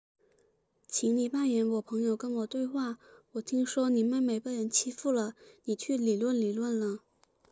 speech generation